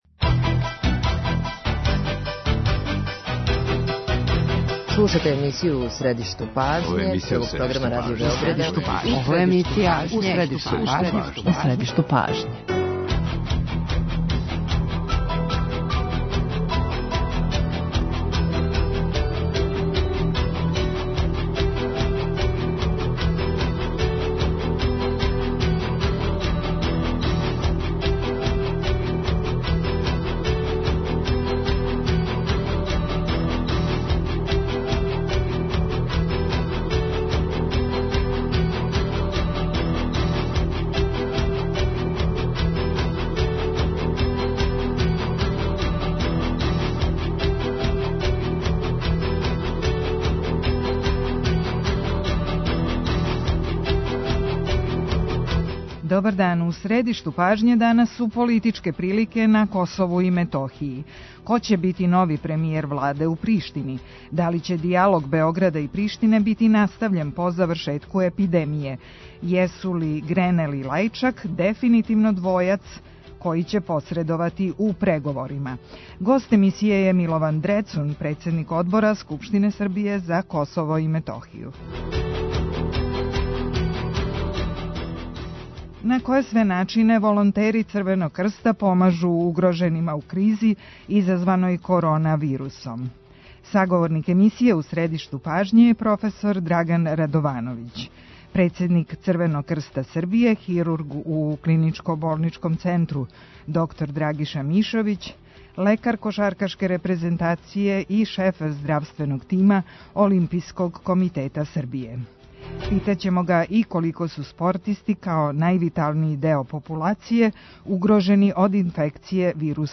Гост емисије је Милован Дрецун, председник Скупштинског одбора за Косово и Метохију.